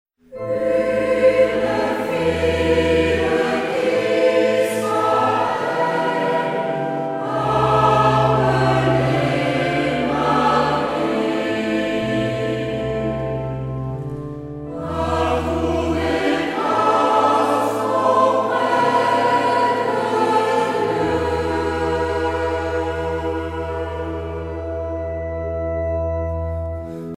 Noël, Nativité
Pièce musicale éditée